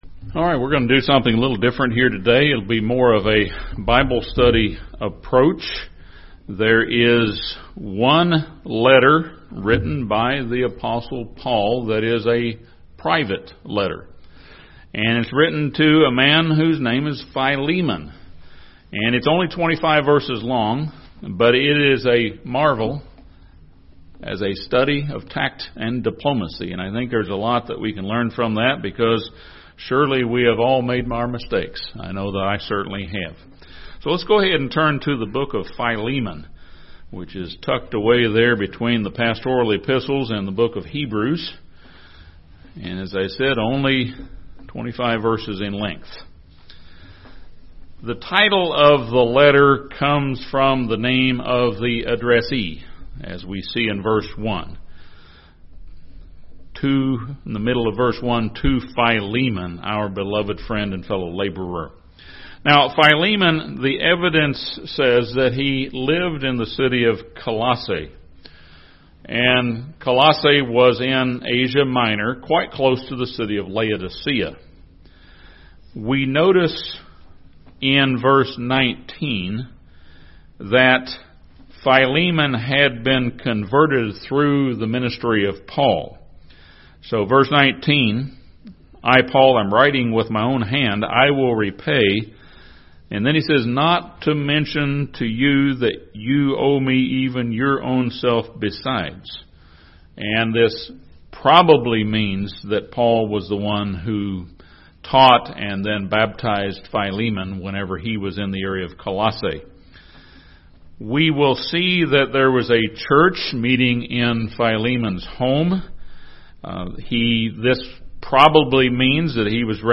This sermon discusses 8 lessons from Philemon that can help improve our ability to get along with others.